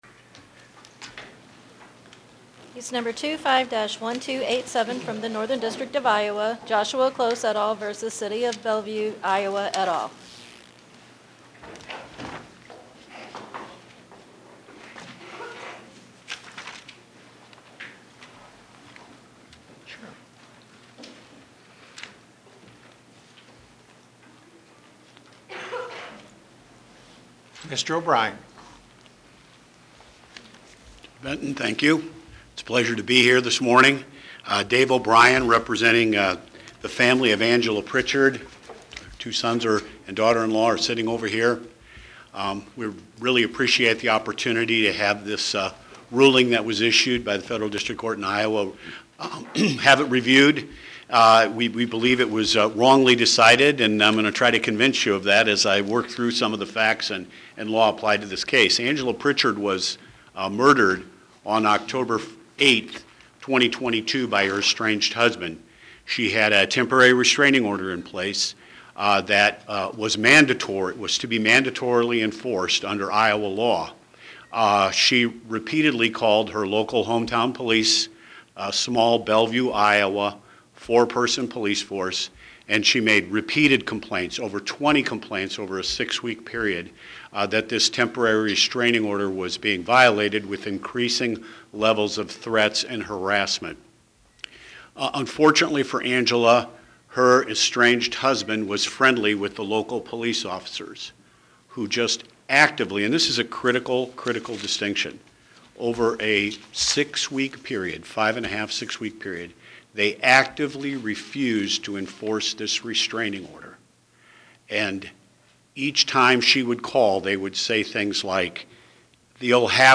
Oral argument argued before the Eighth Circuit U.S. Court of Appeals on or about 11/19/2025